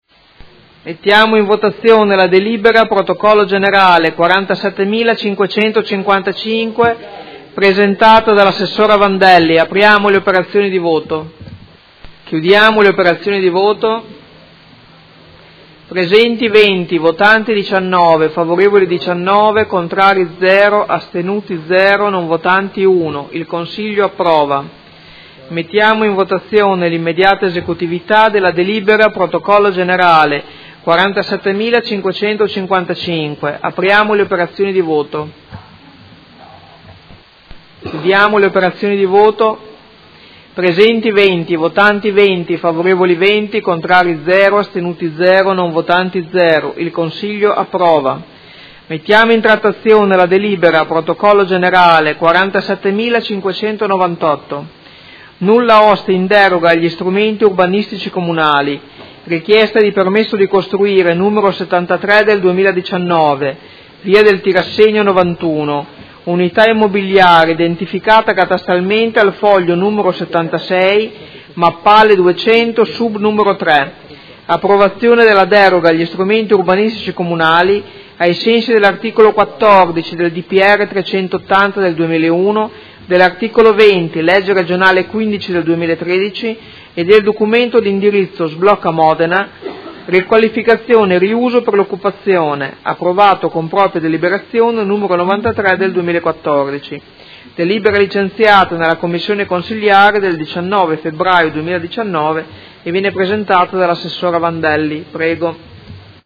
Presidente — Sito Audio Consiglio Comunale
Audio Consiglio Comunale